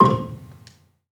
Gamelan
Gambang-C2-f.wav